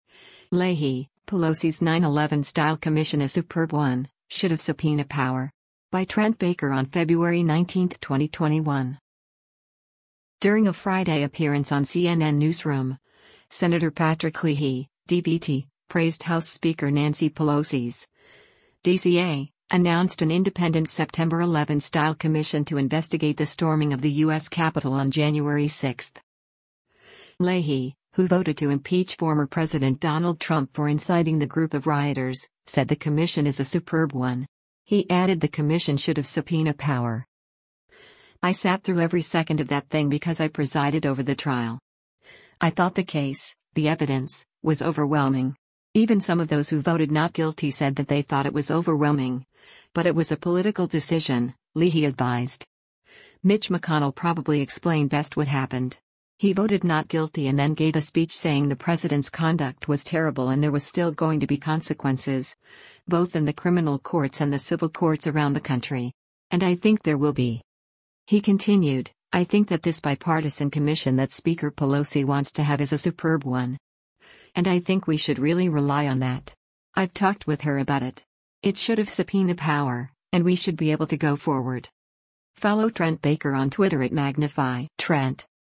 During a Friday appearance on “CNN Newsroom,” Sen. Patrick Leahy (D-VT) praised House Speaker Nancy Pelosi’s (D-CA) announced an independent September 11-style commission to investigate the storming of the U.S. Capitol on January 6.